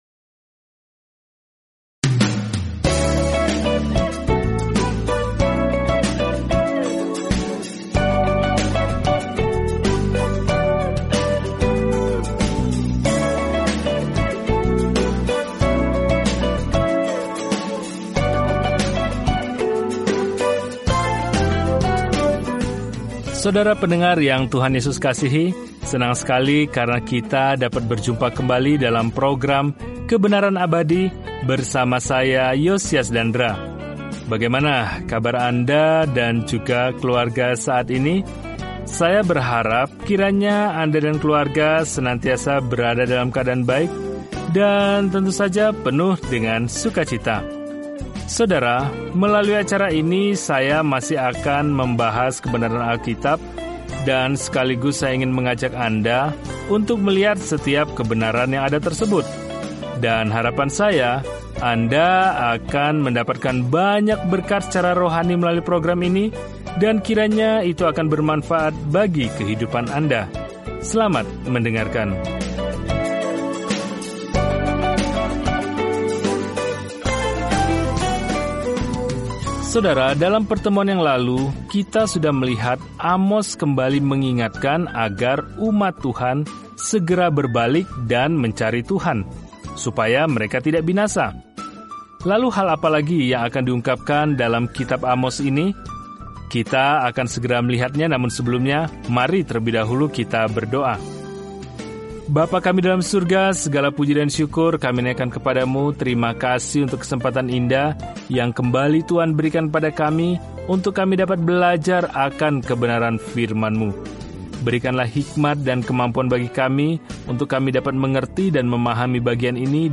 Firman Tuhan, Alkitab Amos 5:4-17 Hari 9 Mulai Rencana ini Hari 11 Tentang Rencana ini Amos, seorang pengkhotbah di pedesaan, pergi ke kota besar dan mengutuk cara-cara mereka yang penuh dosa, dengan mengatakan bahwa kita semua bertanggung jawab kepada Tuhan sesuai dengan terang yang Dia berikan kepada kita. Jelajahi Amos setiap hari sambil mendengarkan studi audio dan membaca ayat-ayat tertentu dari firman Tuhan.